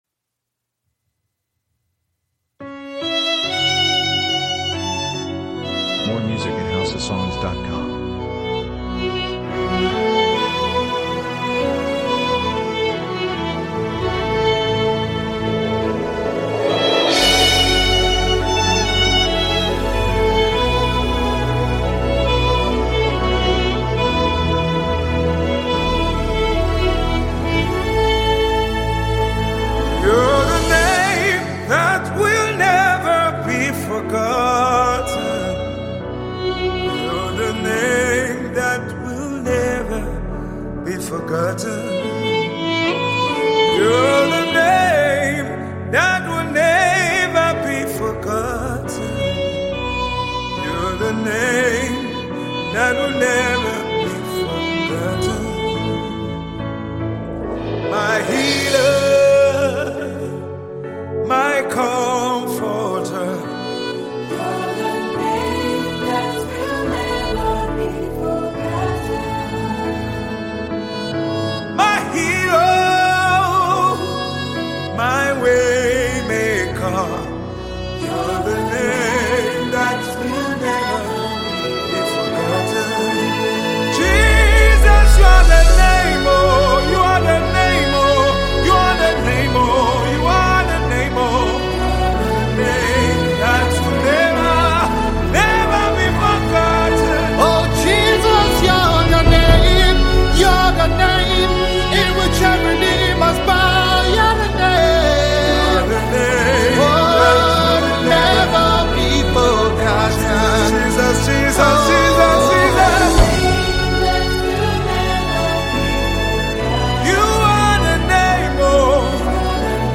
Tiv songs